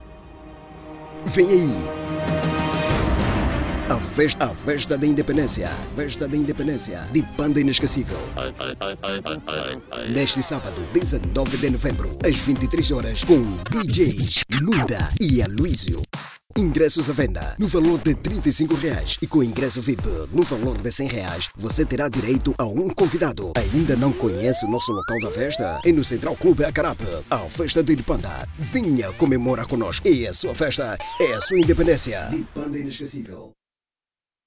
Masculino
Voz Varejo 00:34